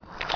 GelDrip.ogg